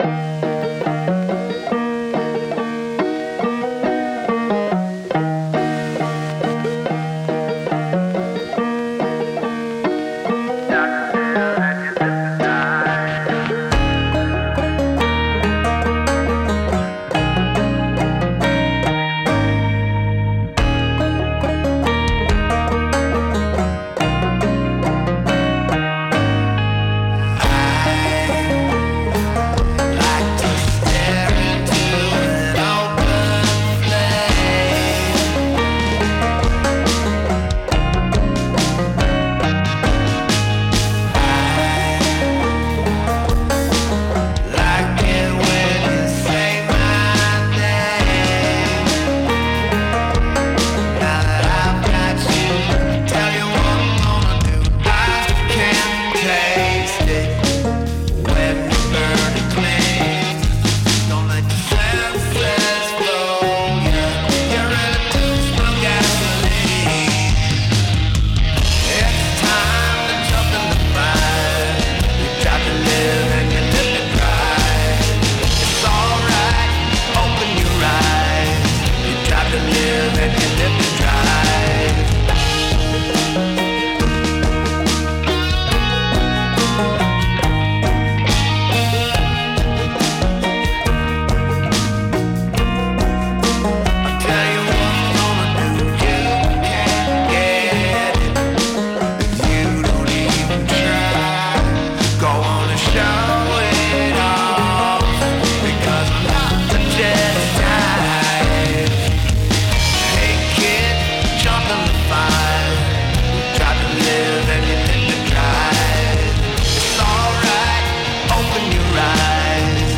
Verse and Chorus Em C Am /// Whistle: Em G D